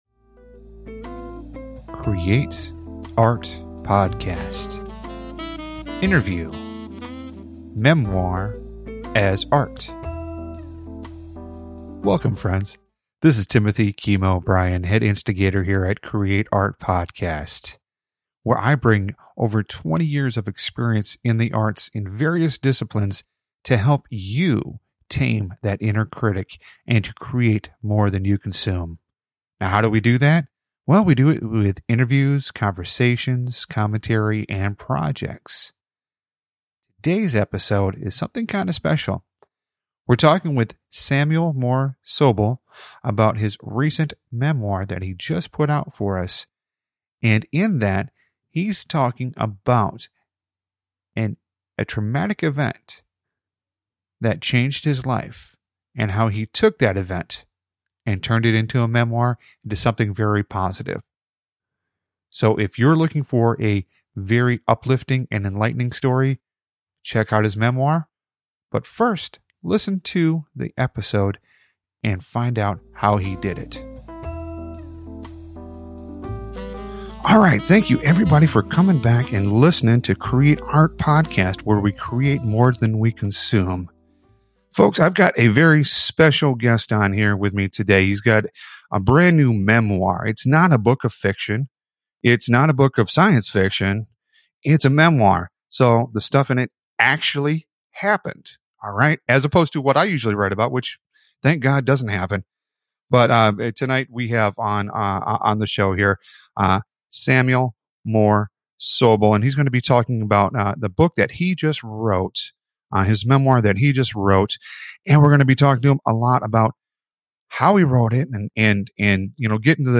INTERVIEW MEMOIR AS ART